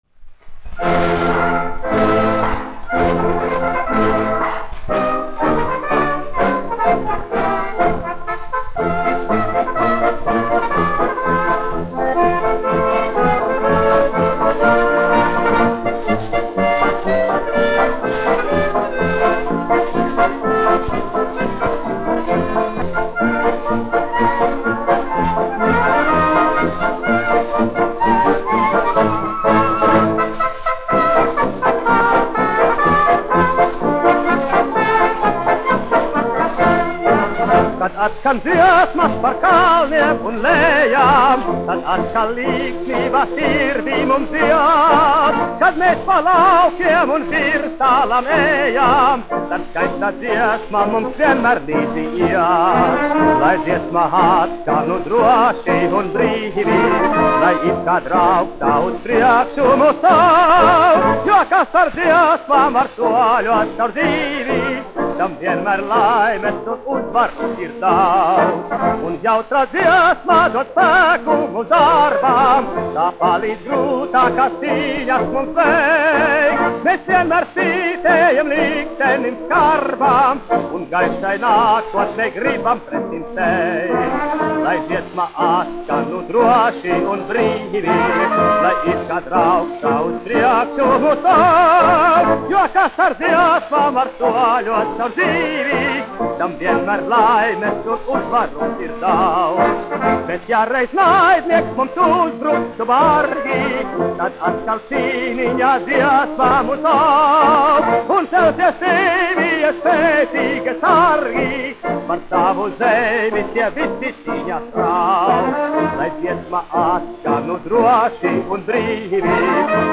1 skpl. : analogs, 78 apgr/min, mono ; 25 cm
Marši
Populārā mūzika
Skaņuplate